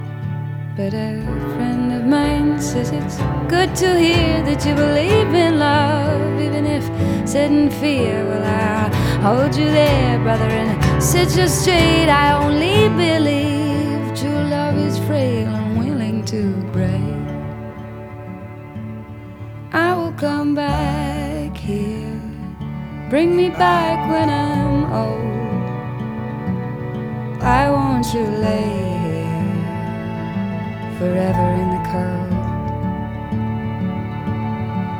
Жанр: Иностранный рок / Рок / Инди / Альтернатива / Фолк-рок